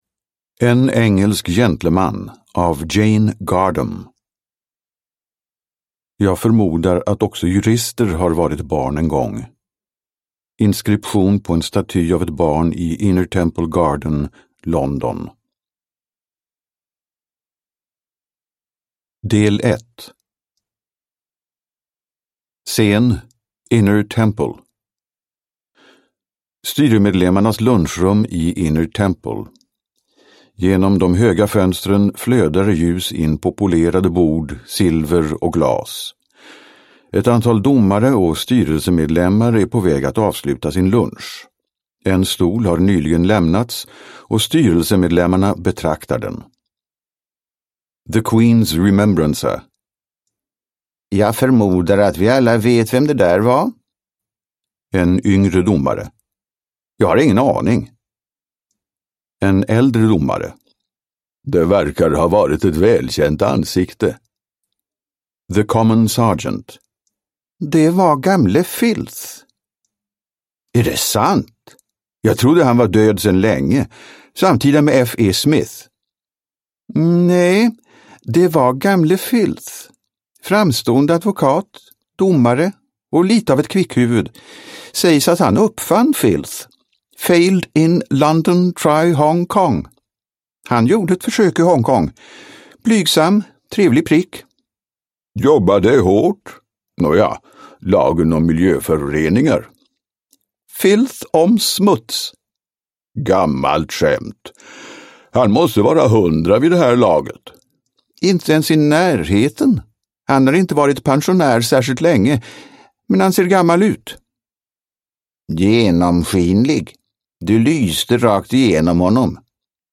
En engelsk gentleman – Ljudbok – Laddas ner